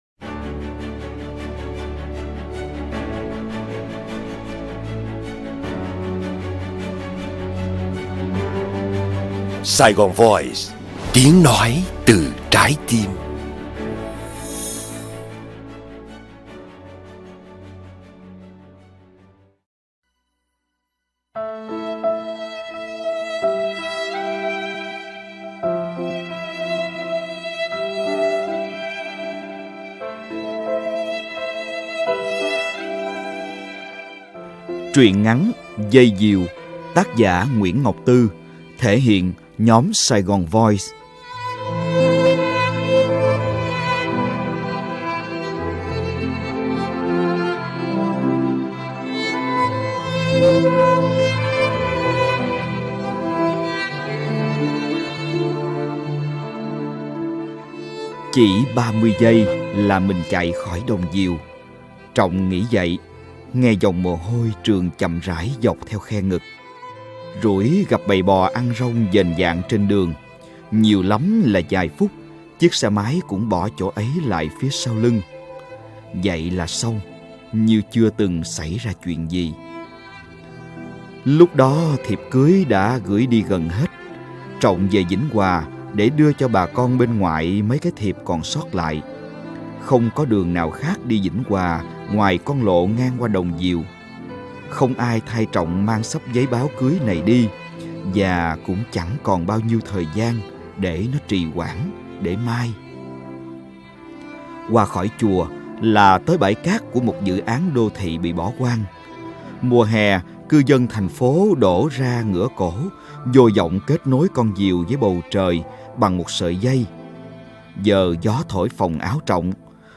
Bạn đang nghe sách nói Dây diều | Nguyễn Ngọc Tư Audio | Bản Full.